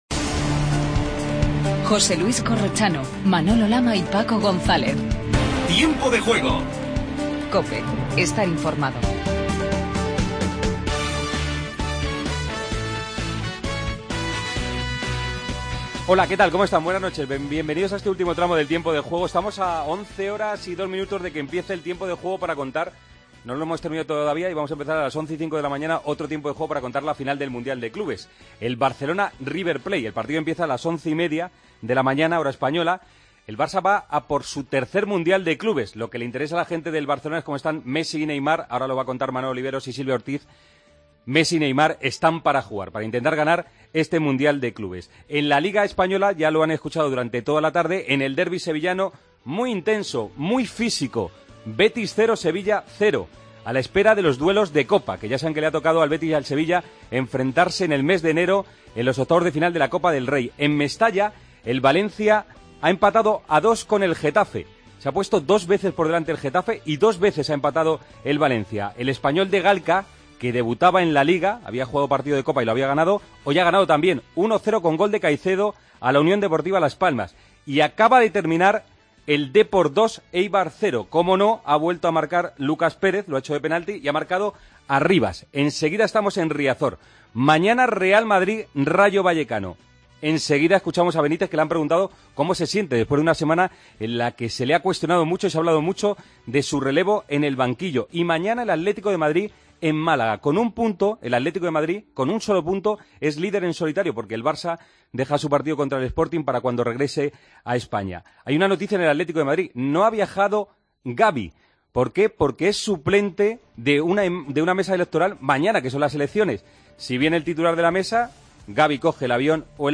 Empezó la jornada 16 de la Liga BBVA: Valencia, 2 – Getafe, 2; Espanyol, 1 – Las Palmas, 0; Betis, 0 – Sevilla, 0 y Deportivo, 2- Eibar, 0. Hablamos con Ceballos y Adán. Entrevista a Solari sobre el Barcelona - River
Con Paco González, Manolo Lama y Juanma Castaño